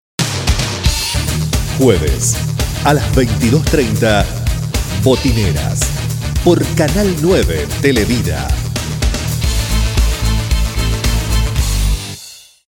spanisch SĂŒdamerika
Sprechprobe: eLearning (Muttersprache):